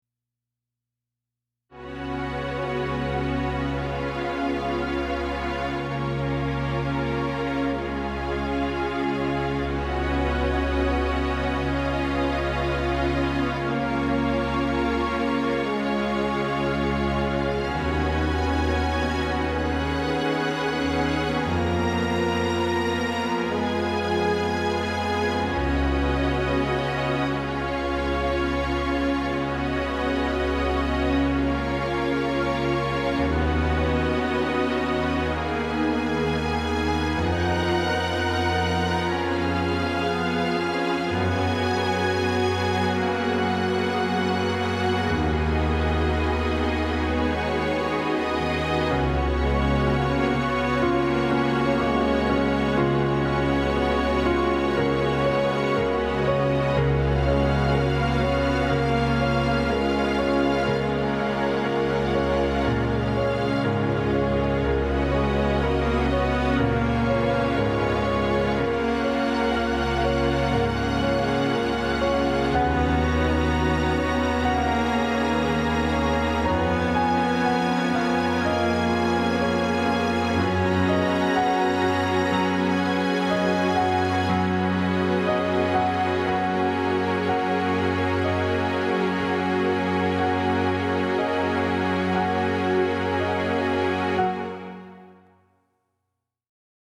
Ambient: